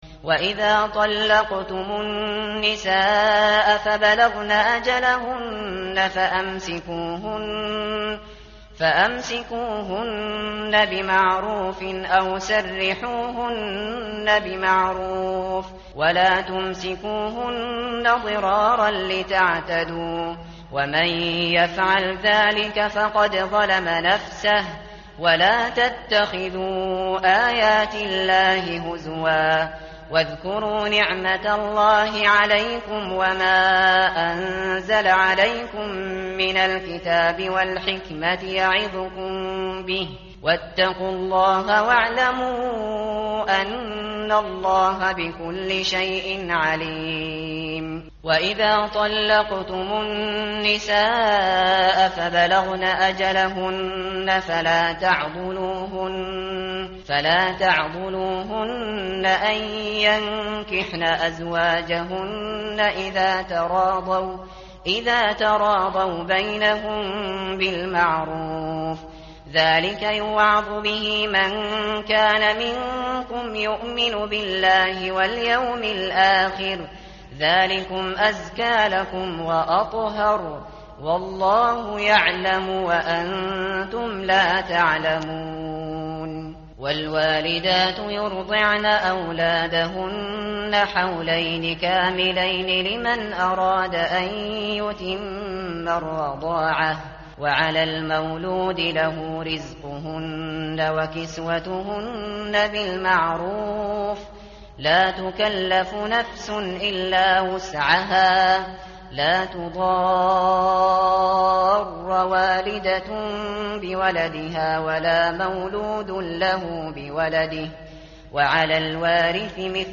tartil_shateri_page_037.mp3